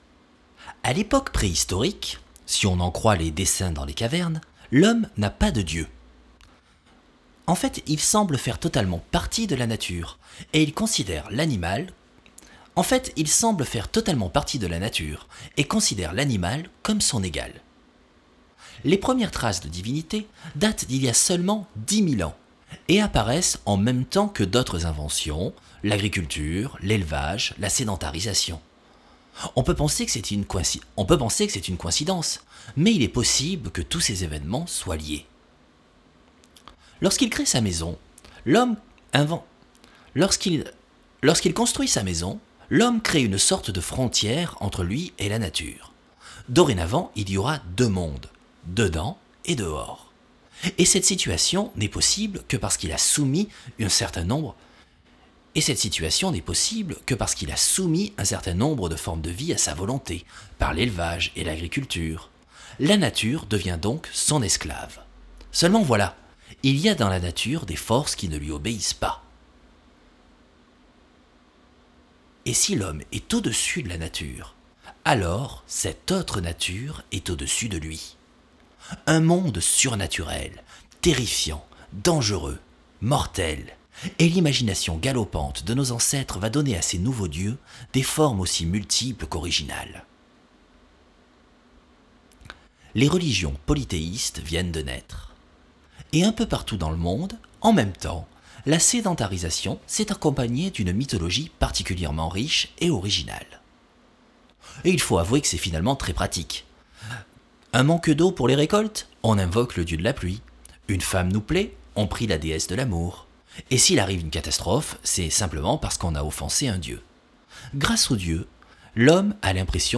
voix off.aif